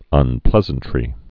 (ŭn-plĕzən-trē)